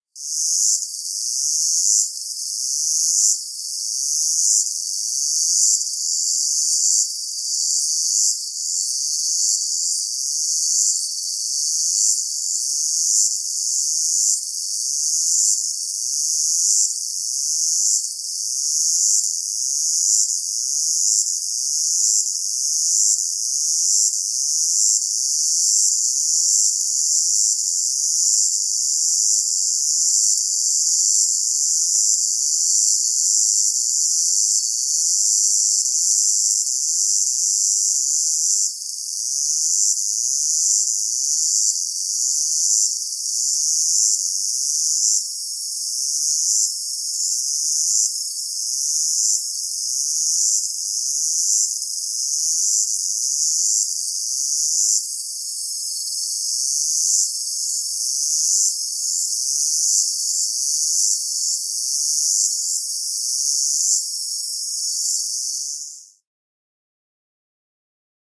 Cicadas emit species-specific tymbalizations (songs), which are available online as supporting material to this volume (111 downloadable audio tracks).
Cicada tymbalization downloads